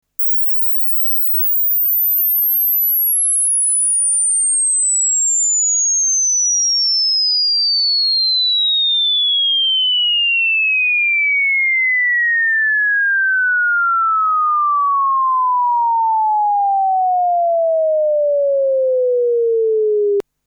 スウィープ信号 -12.0dB (20kHz-400Hz; Sine; Stereo)